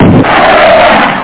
missile1.mp3